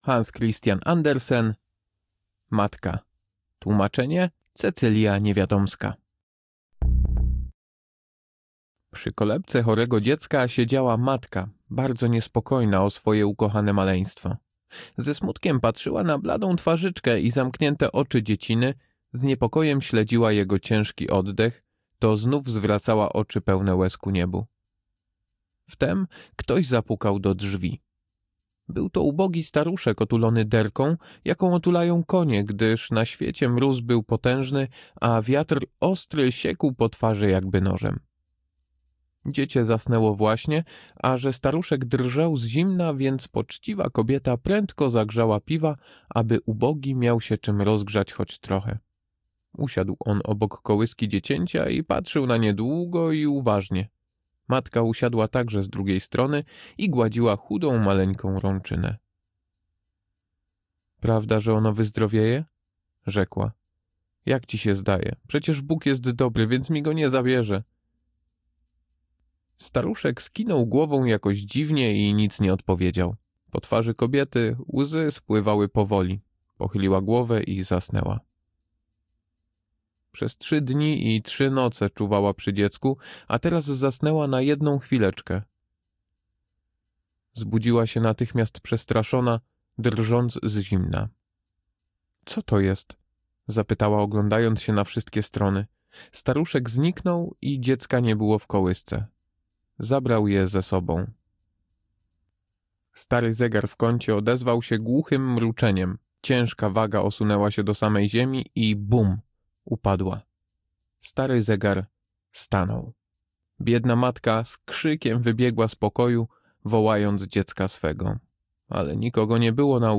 Tematy: Baśń
Audiobook